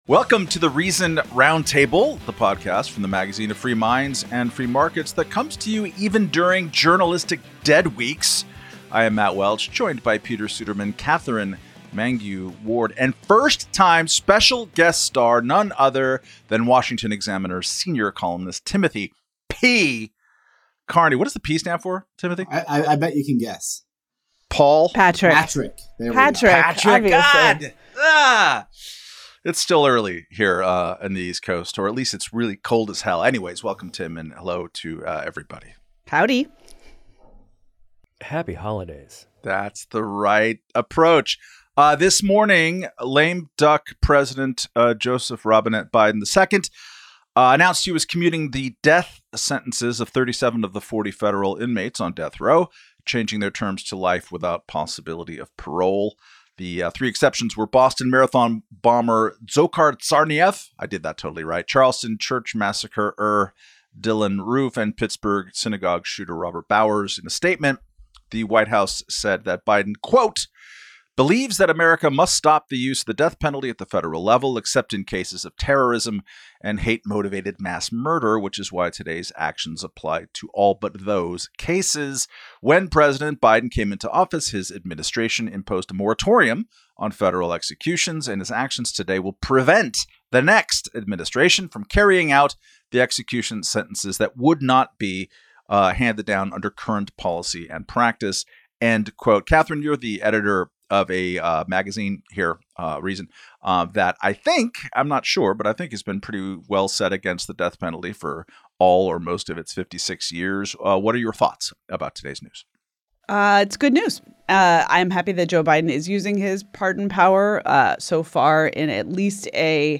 The panelists discuss Joe Biden's commutations of 37 out of 40 federal death row sentences, before unpacking the latest on the traditional end-of-year struggle in Congress to pass a spending deal.